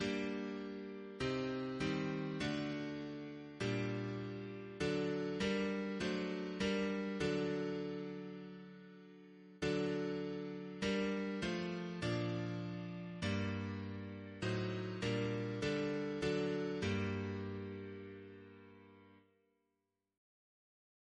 Double chant in G minor Composer: James Turle (1802-1882), Organist of Westminster Abbey Note: after J.S.Bach Reference psalters: ACB: 376